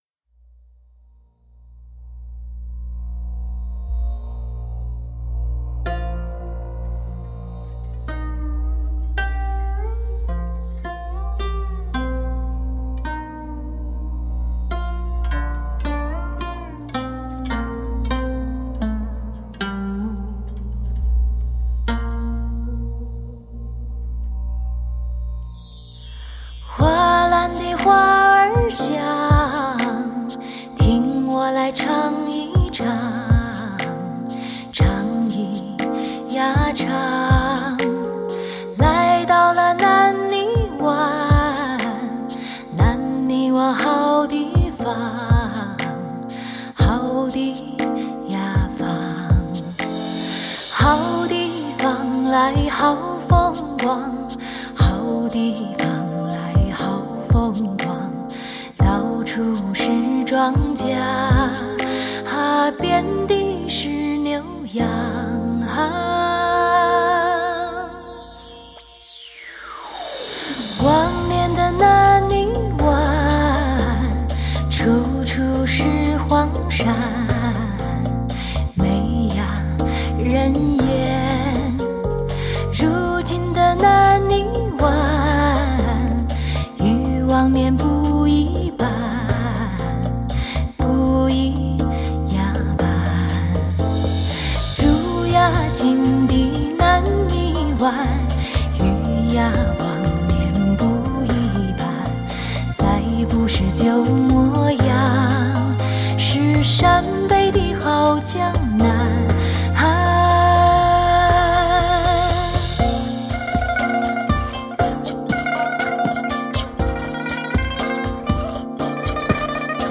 空灵之音沁人心肺 温暖抚慰的天籁女声